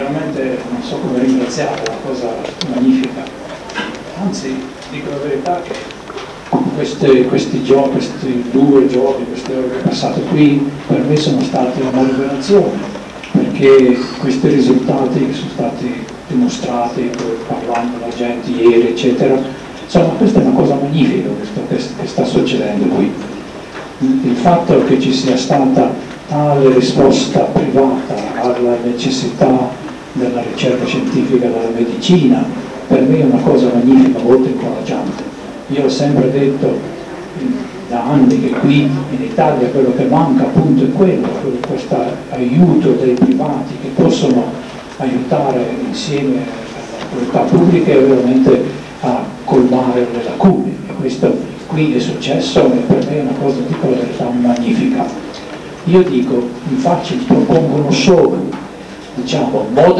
A prologo della sua Lettura, il Professore ha avuto